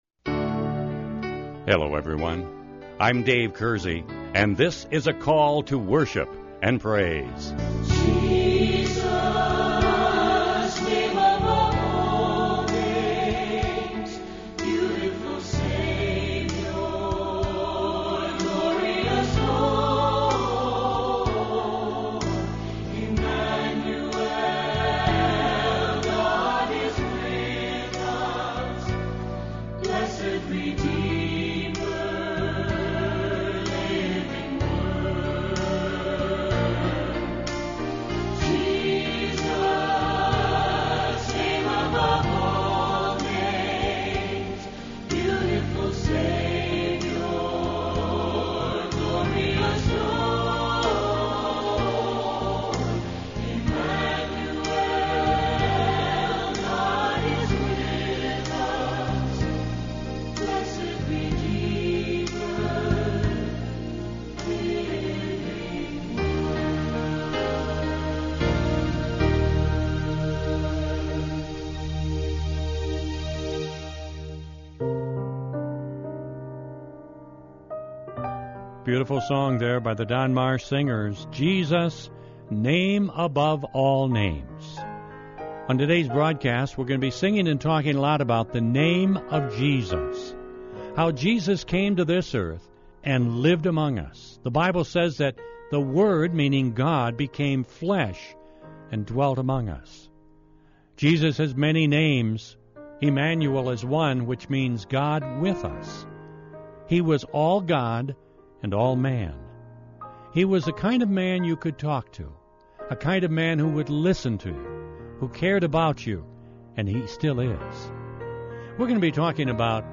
This week’s Devotional Topic This week we will be singing and talking about the many names of Jesus. We will share a beautiful devotion by Max Lucado called, “Just Call Me Jesus”.